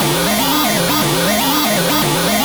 TRASHY SEQ-L.wav